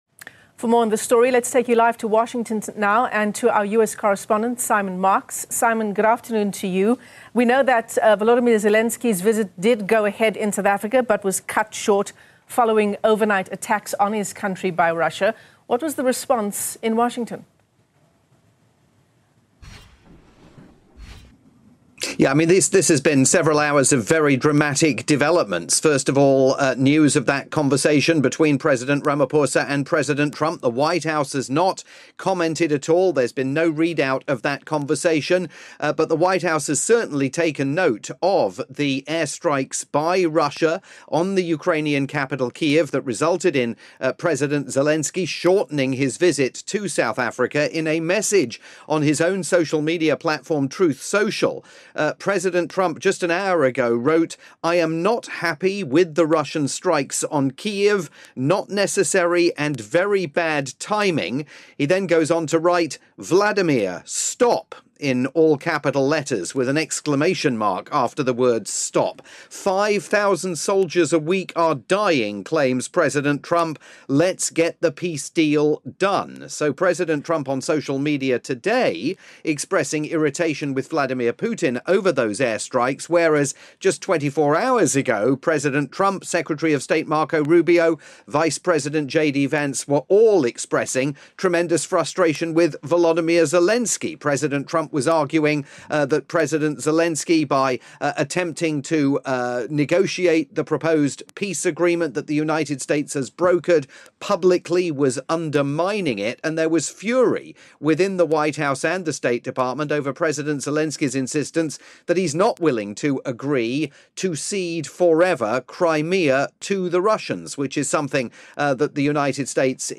live update